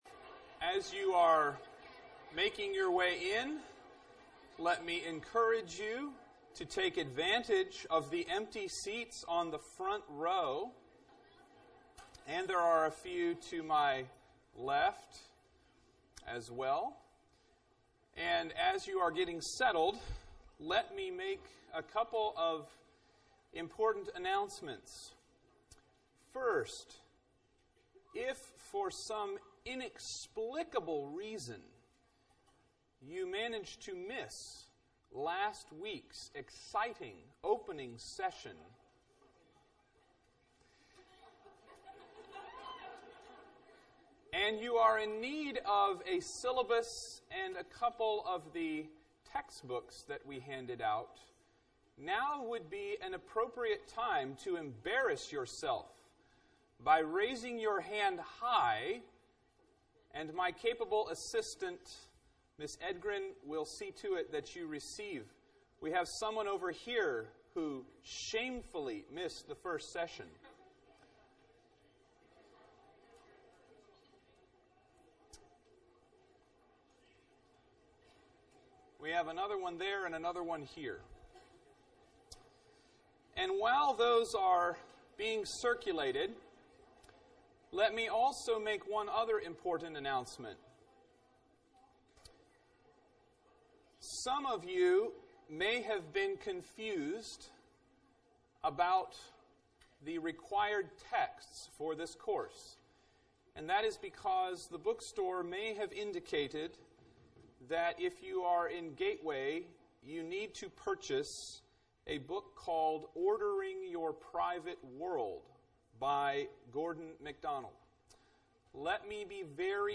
Address: The Cultural Road